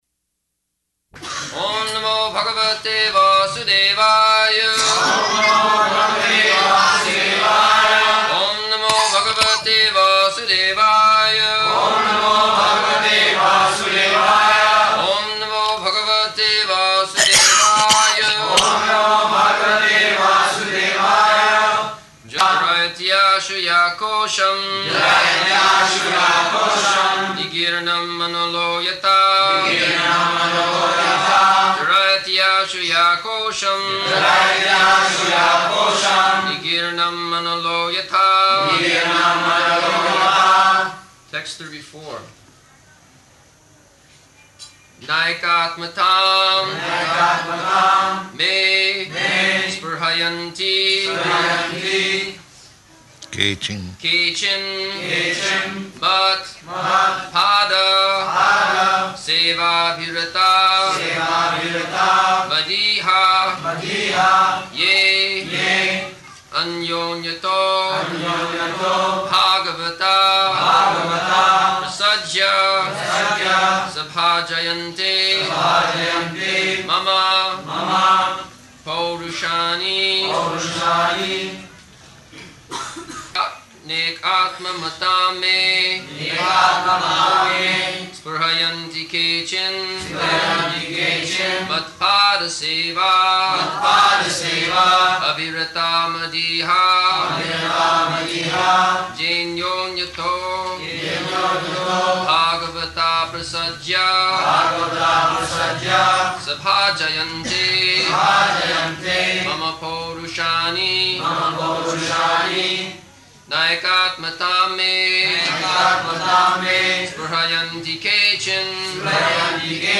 December 3rd 1974 Location: Bombay Audio file
[devotees repeat]